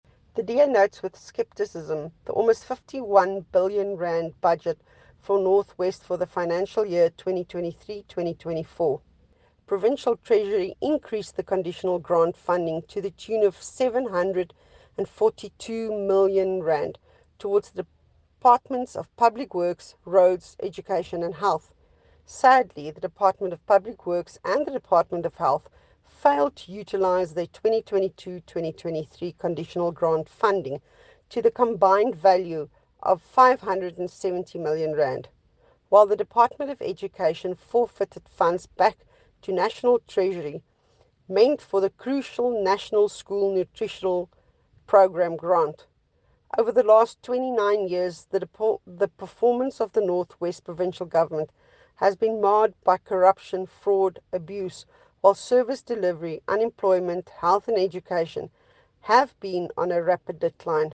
Issued by Jacqueline Theologo MPL – DA North West Spokesperson on Finance
Note to Broadcasters: Find linked soundbites in